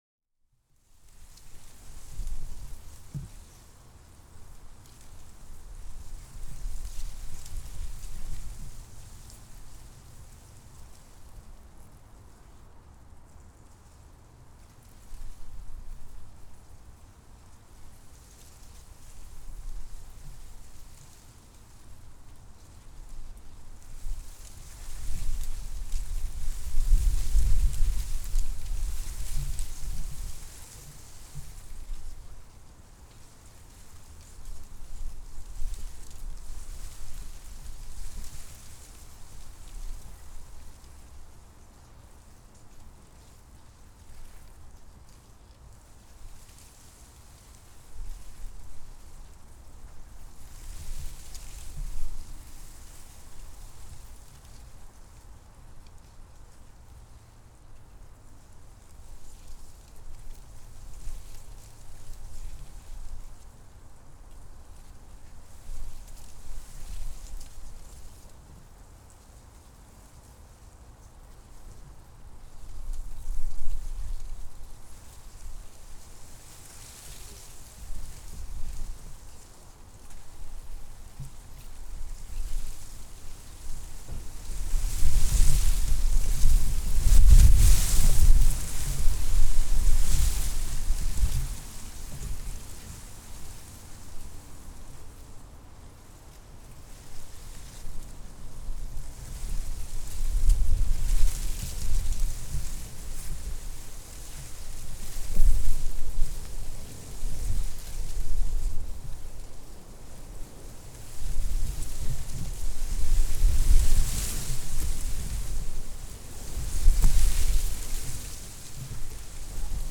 Schilf1_ITU51.mp3